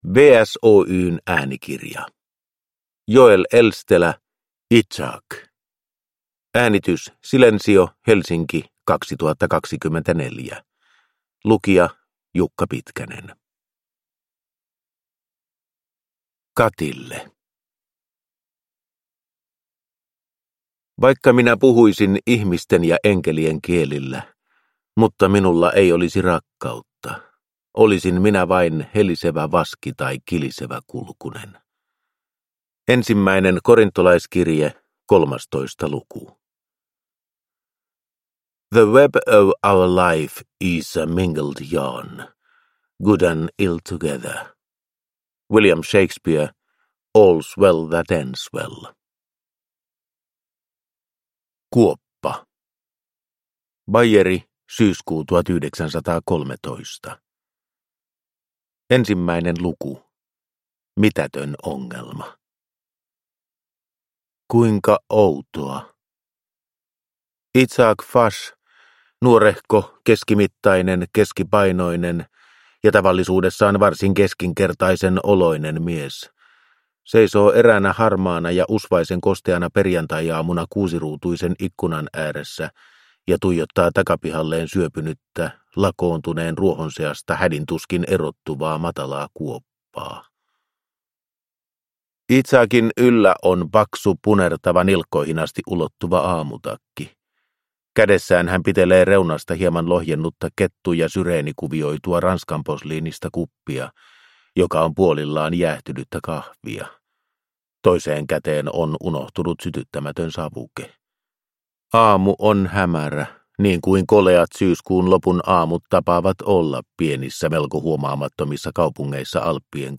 Izak (ljudbok) av Joel Elstelä